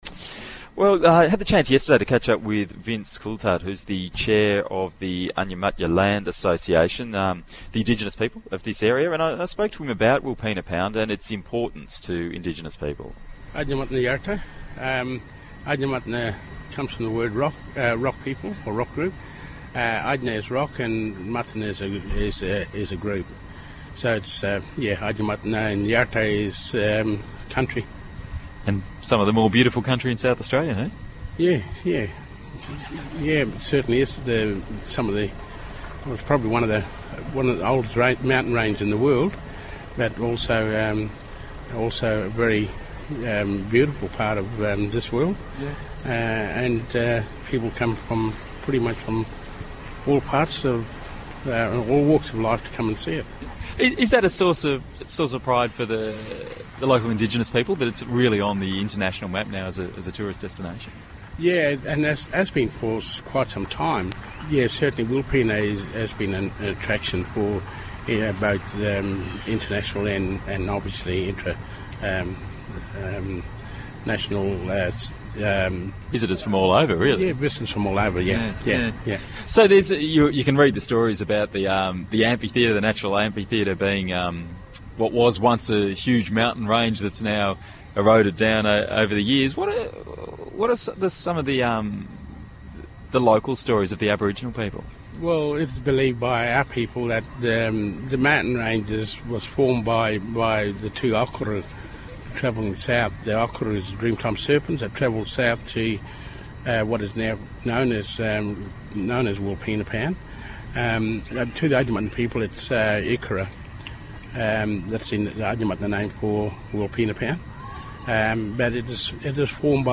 ABC Radio National